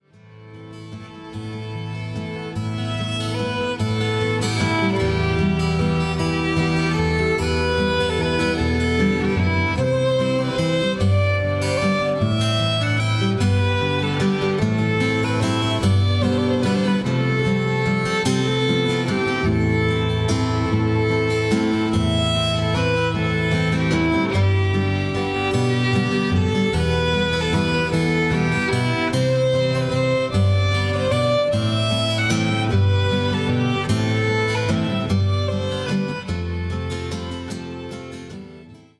a collection of danceable waltzes.